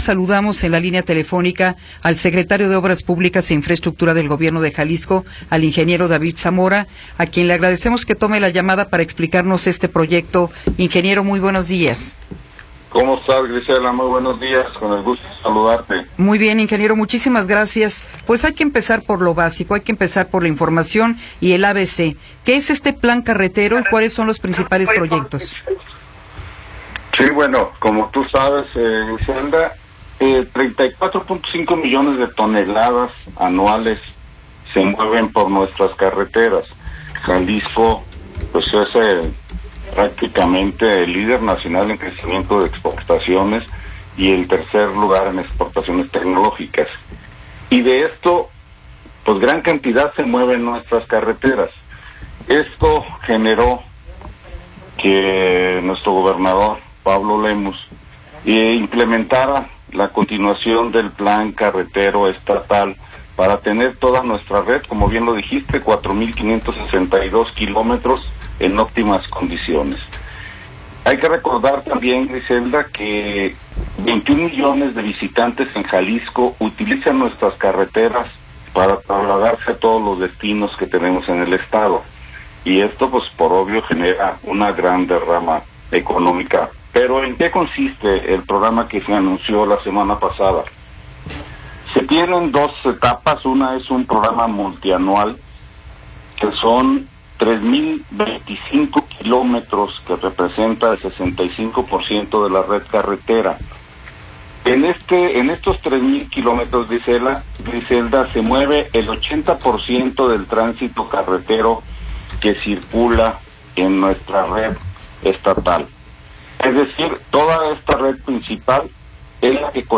Entrevista con David Zamora Bueno
David Zamora Bueno, secretario de infraestructura y obra pública del estado de Jalisco, nos habla sobre el plan carretero estatal 2025-2030.